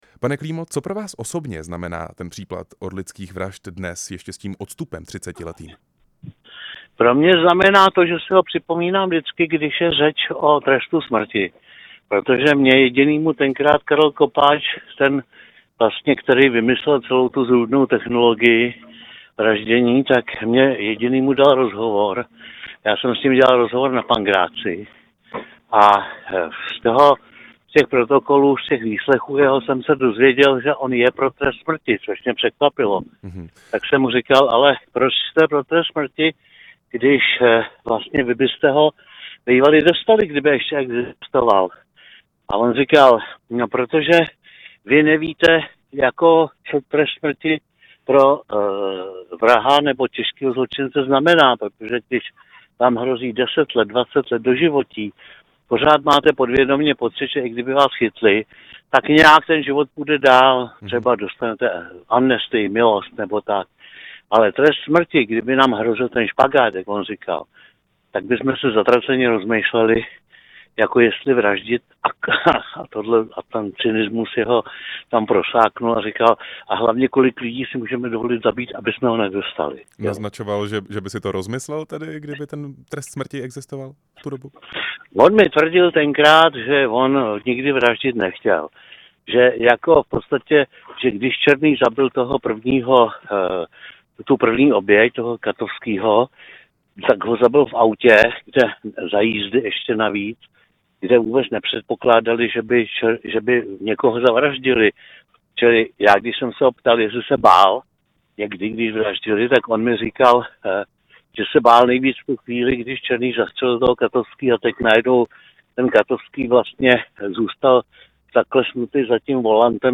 Byl hostem vysílání Rádia Prostor.
Rozhovor s investigativním novinářem Josefem Klímou